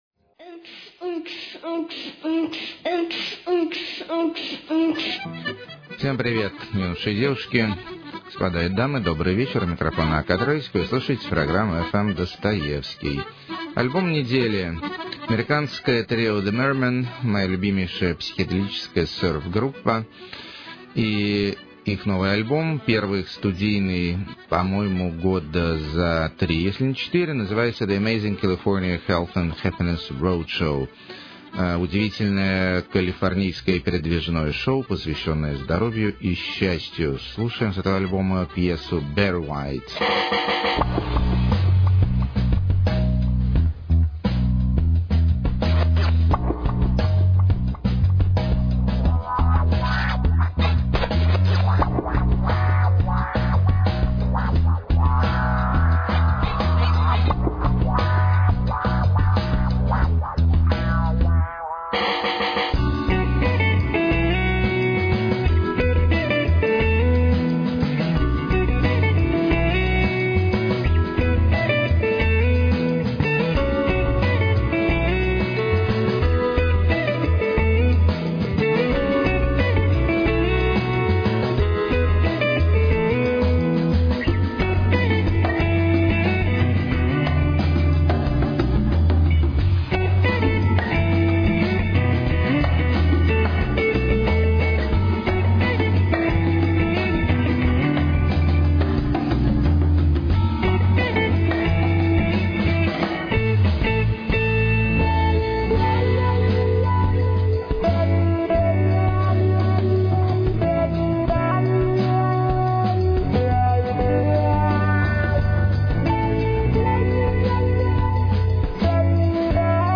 Сварливый Итальянский Шансон.
Современная Английская Молодежная Гитарная Музыка.
Циничная Полярная Электронная Декадентская Музыка.
Танцы Под Компьютер И Сёрф-гитарку.